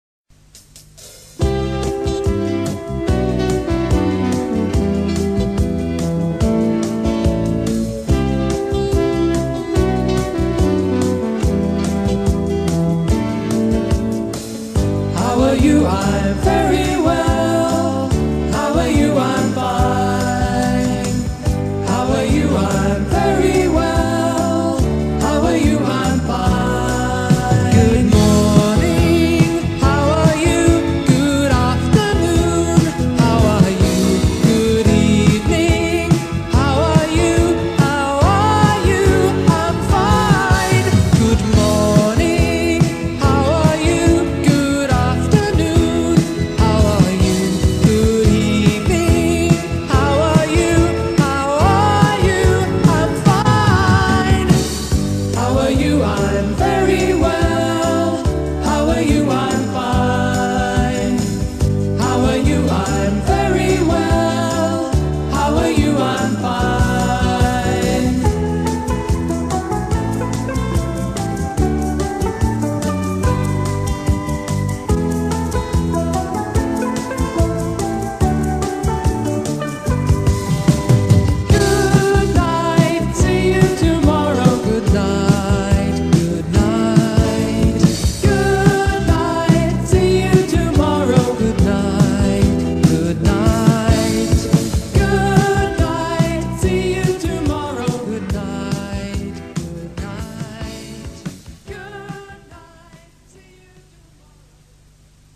Aprende Gramática Cantando
con esta canción no comercial